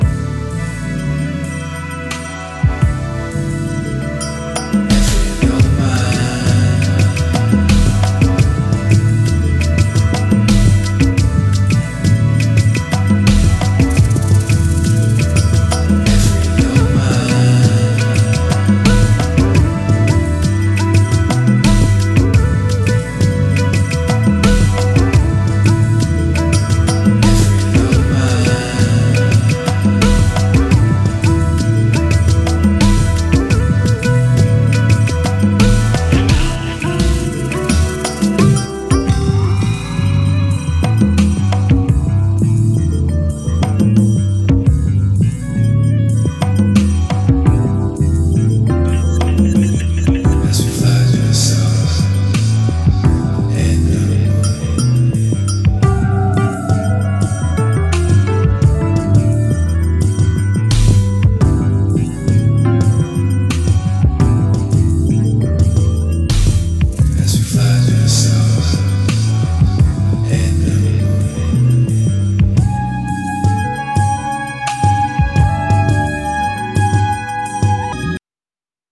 秀逸なディープ・ハウスをじっくり堪能できるおすすめ盤です！